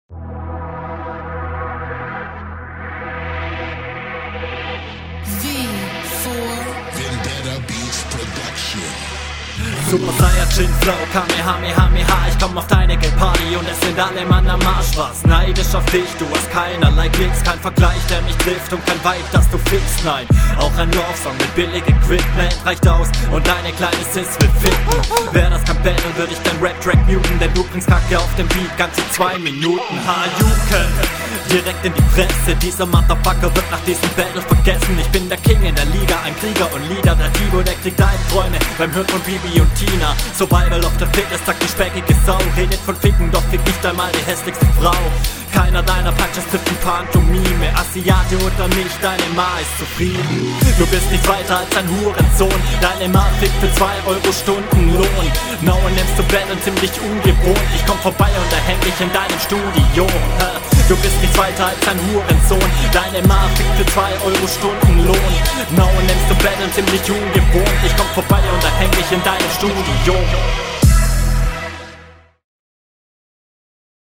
Hier könnte der Beat etwas lauter..
Flowst richtig cool auf dem Beat, deine Reime haben sich auch verbessert.
Mische gefällt mir hier wieder besser.
Beat etwas zu laut aber auch hier kommt du sehr nice.